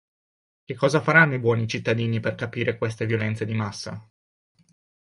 cosa a 🐌 Meaning Concepts Synonyms Translations Notes Practice pronunciation Extra tools Noun Pron Frequency A1 Hyphenated as cò‧sa Pronounced as (IPA) /ˈkɔ.za/ Etymology Inherited from Latin causa.